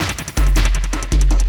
53LOOP01SD-R.wav